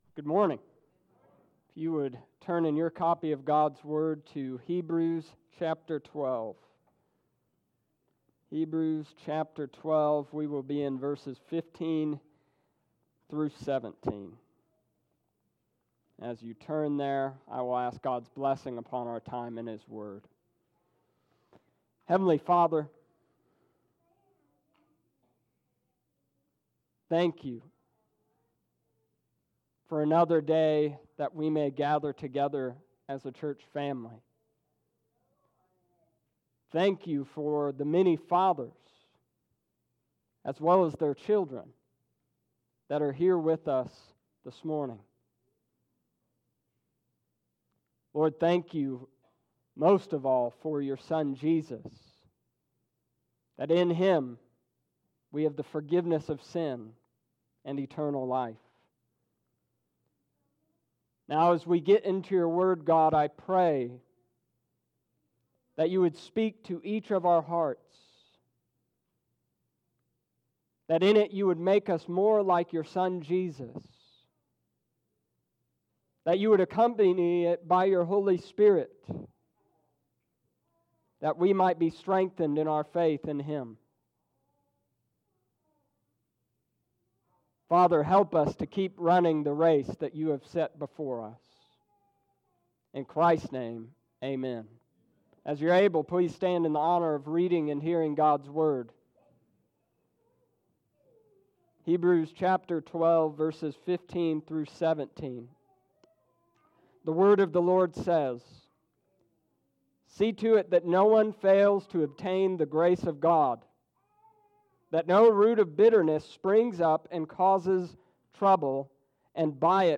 Summary Of Sermon First Keep running by watching over each other ( verses 15-16) < Keep watch that all may reach grace..